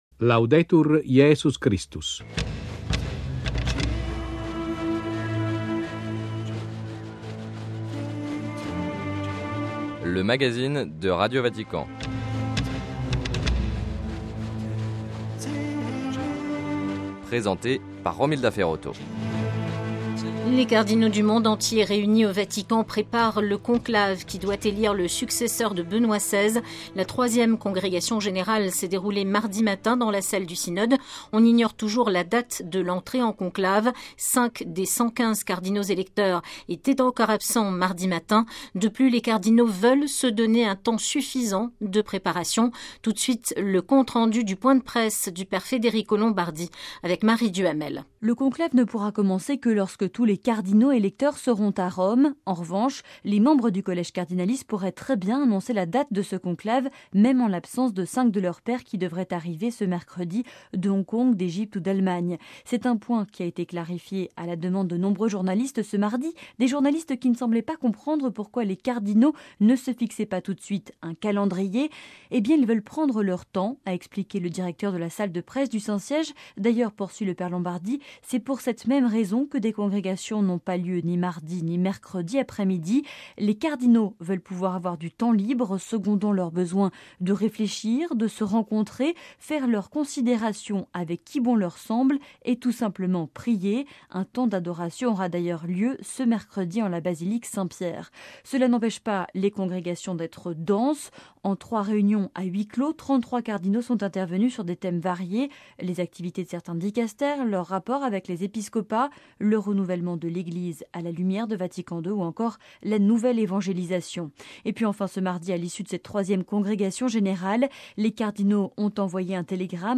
- Entretien avec le cardinal français Philippe Barbarin, archevêque de Lyon. - Des cardinaux acceptent de répondre aux questions des journalistes.
- Extrait du discours adressé par Benoît XVI au corps diplomatique en janvier 2011.